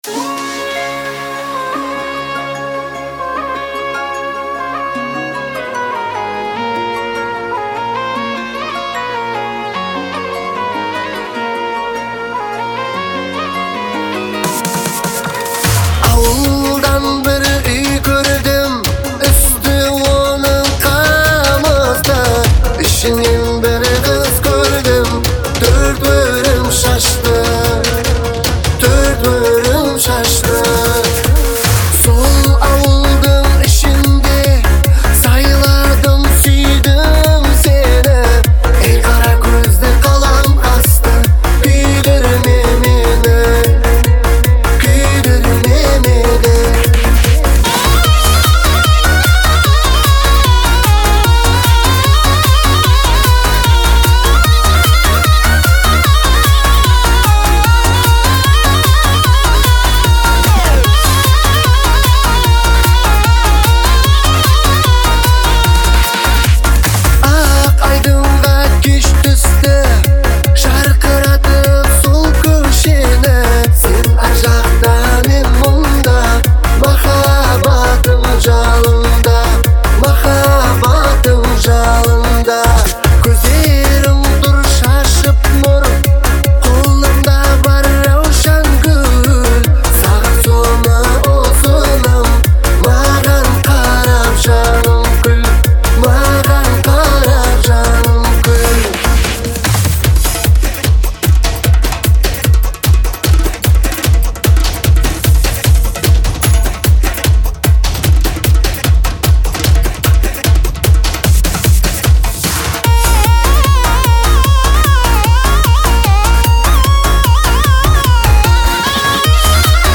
мягкими мелодиями и чувственным вокалом